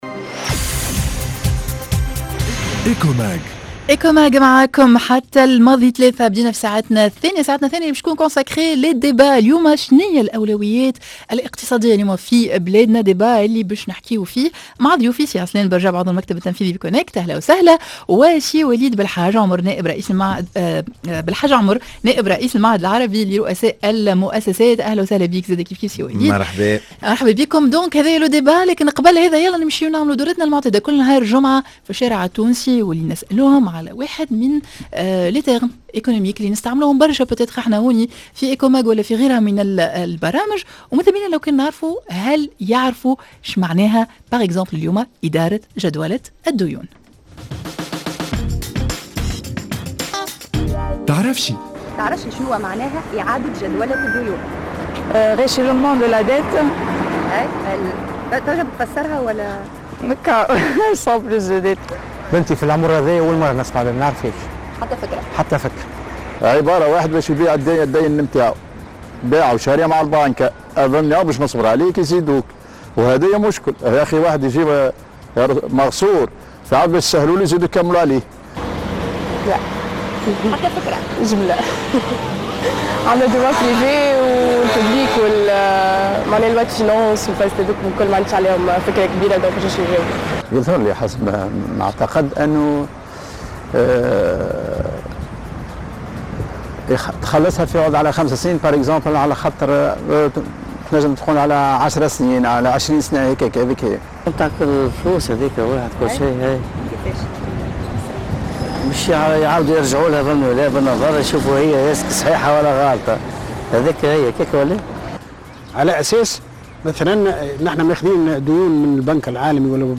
Le débat: ماهي أولويات الدولة الإقتصادية اليوم؟